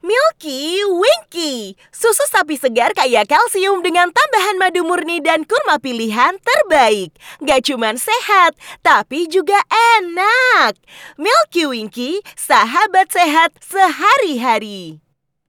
Kommerziell, Tief, Erwachsene, Warm, Corporate
Kommerziell